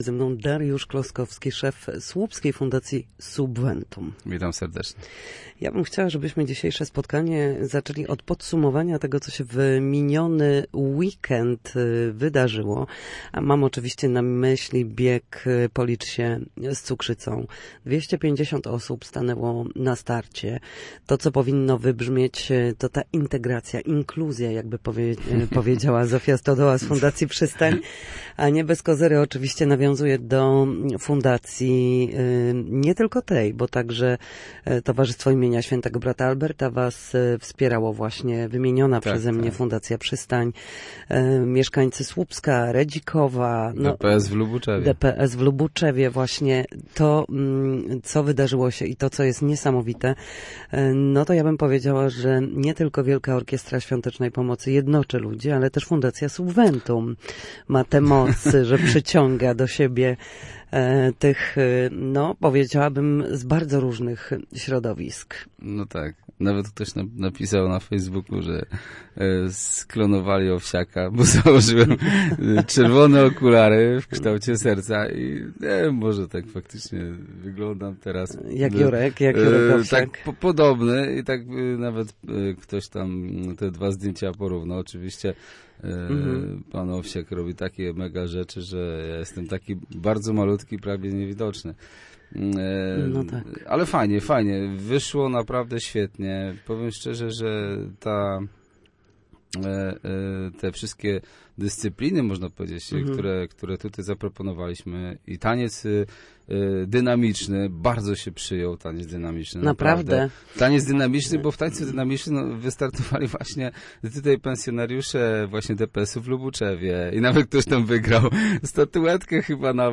w słupskim studiu Radia Gdańsk